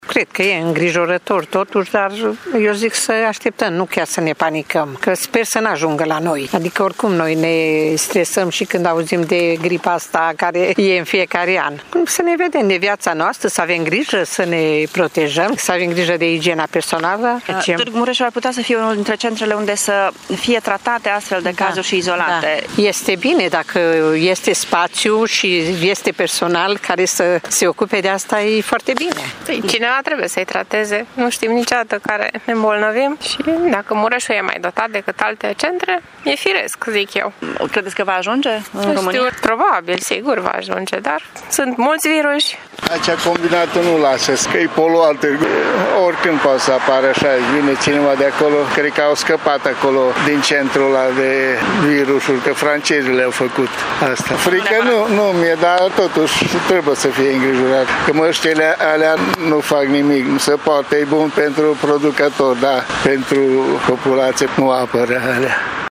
Unii târgumureșenii cred că e normal ca astfel de îmbolnăviri să poată fi tratate și la Târgu-Mureș, însă recunosc că acest coronavirus provoacă îngrijorare: